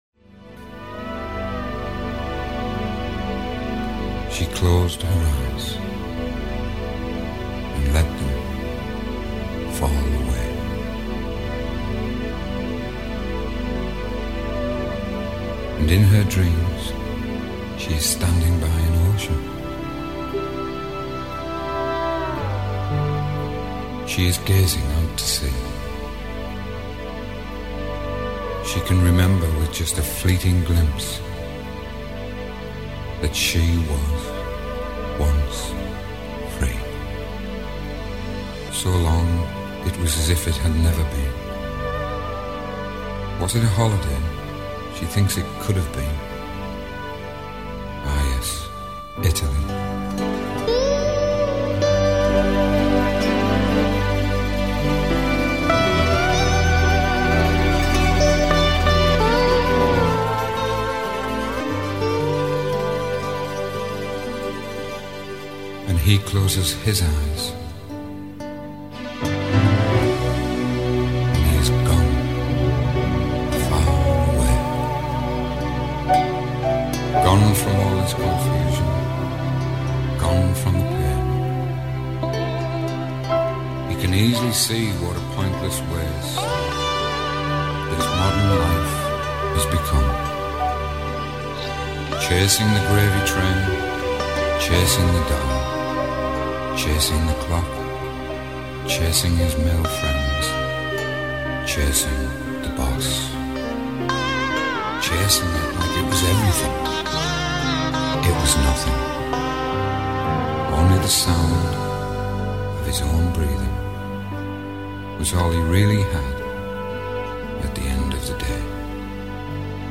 belle et triste chanson